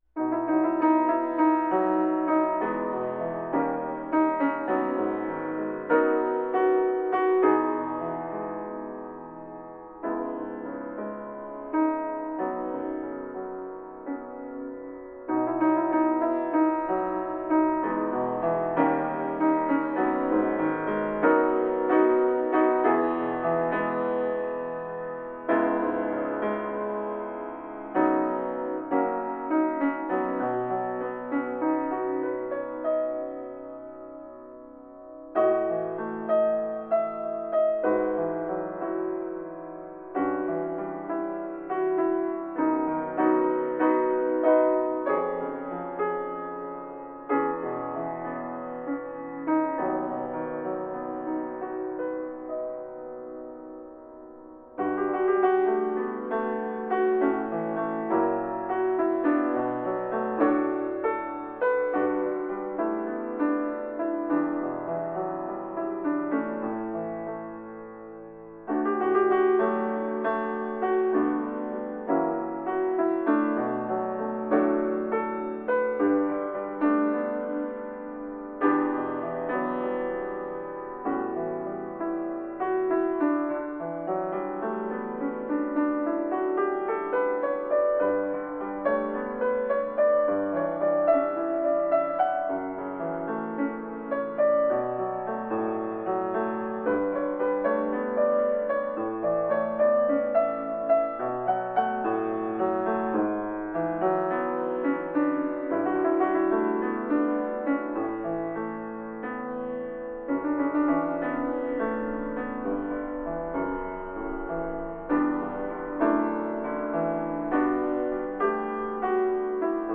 - 生ピアノ（生演奏） 切ない